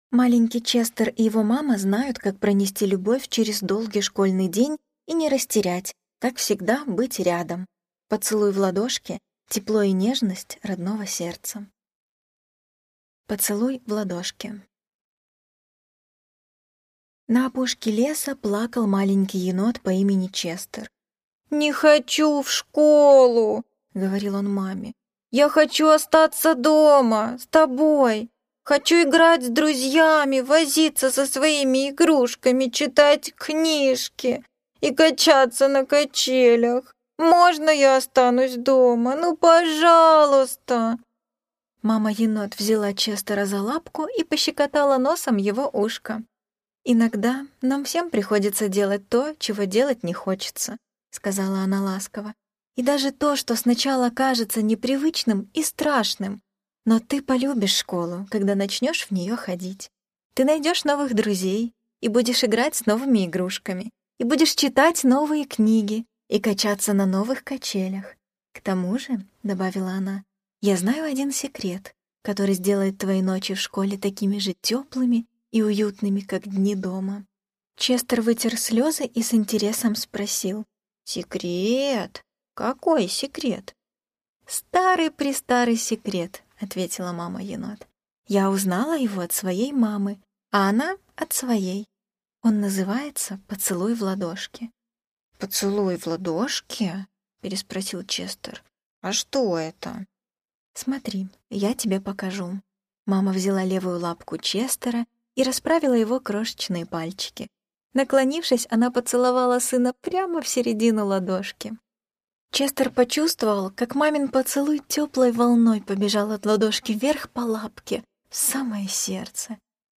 Поцелуй в ладошке - аудиосказка Одри Пенн - слушать онлайн